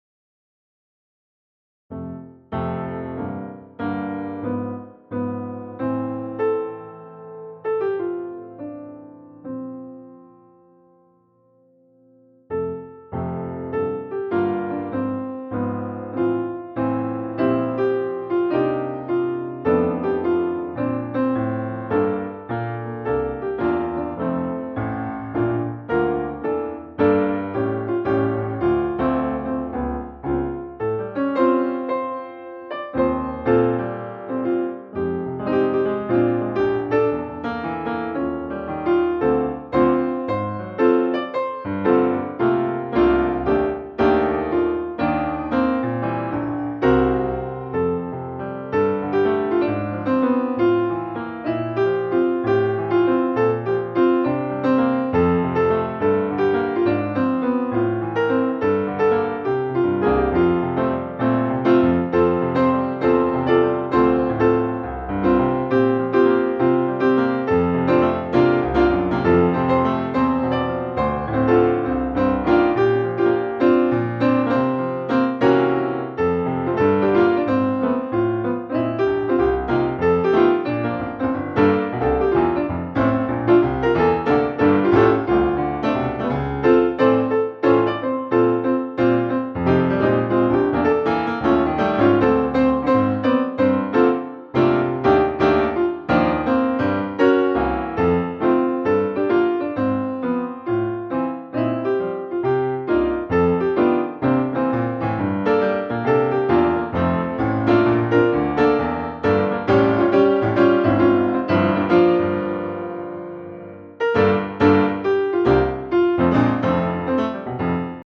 Wochenlied für den 21.06.2020